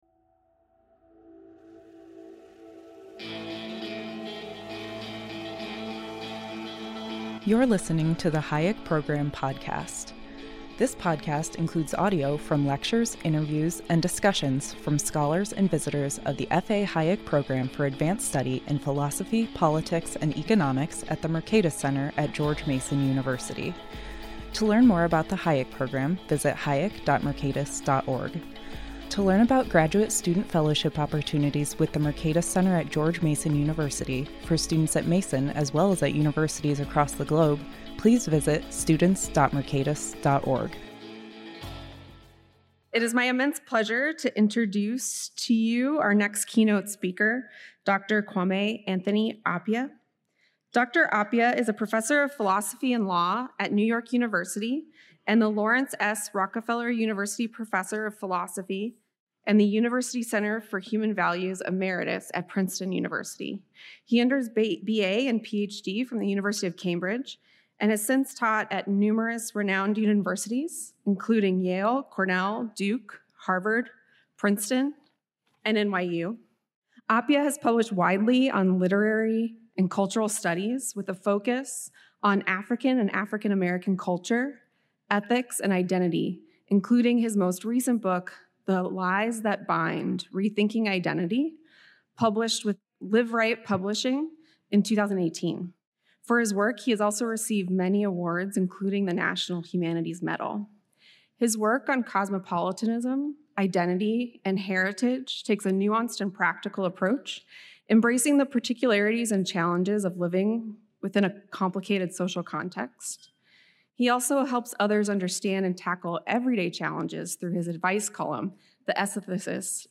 The Hayek Program Podcast includes audio from lectures, interviews, and discussions of scholars and visitors from the F. A. Hayek Program for Advanced Study in Philosophy, Politics, and Economics at the Mercatus Center at George Mason University.